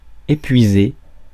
Ääntäminen
IPA: /e.pɥi.ze/